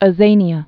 (ə-zānē-ə, ə-zānyə)